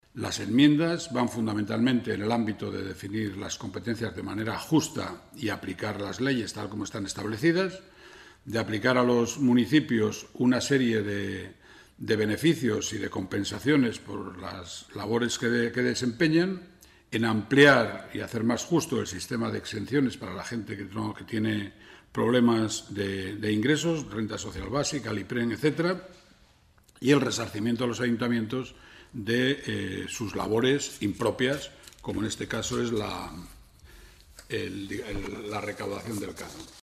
Así lo ha dado a conocer este viernes en rueda de prensa el portavoz del PRC, Rafael de la Sierra, quien ha explicado que el objetivo global de estas enmiendas es definir las competencias “de manera justa” y aplicar las leyes “tal como están establecidas”, conceder a los ayuntamientos beneficios y compensaciones por las labores que desempeñan y “resarcirles” por realizar labores que no son de su competencia, como la recaudación del canon de saneamiento, y “hacer más justo” el sistema de exenciones para las personas con menores ingresos.
Rafael de la Sierra, portavoz del Grupo Parlamentario Regionalista